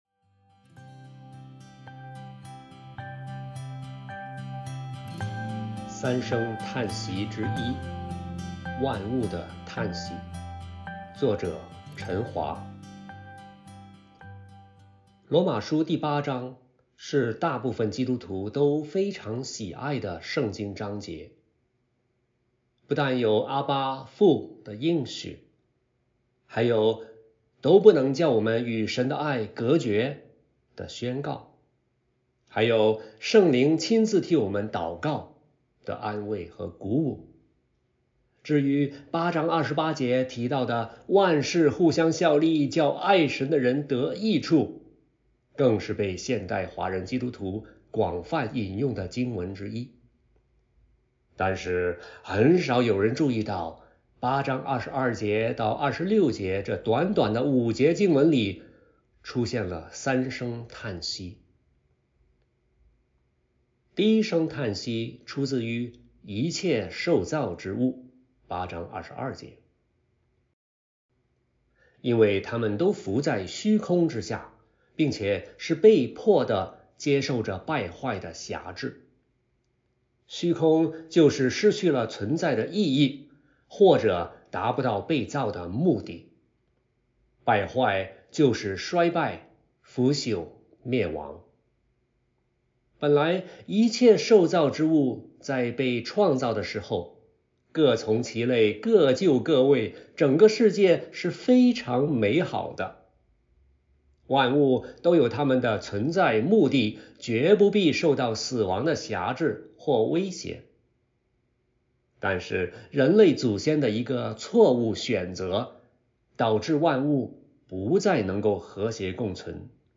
音频朗读